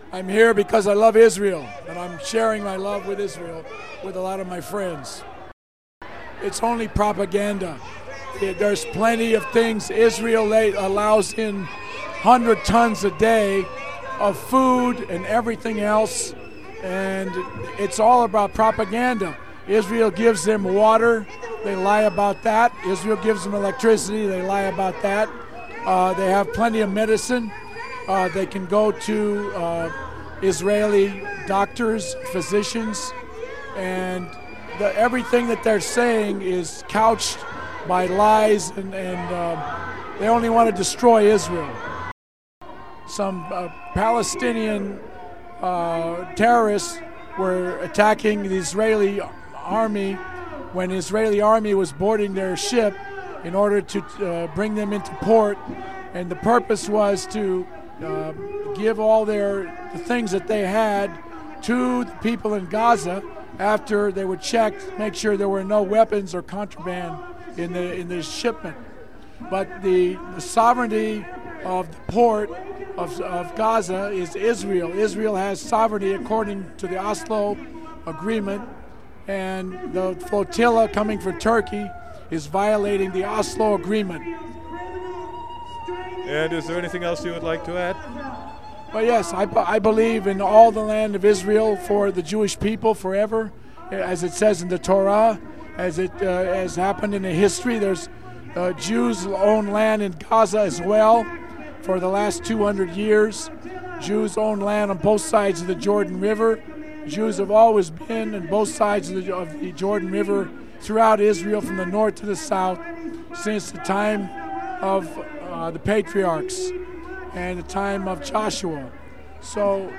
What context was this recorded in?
Two speech excerpts and three interviews from the 6/4/10 protest at the Israeli consulate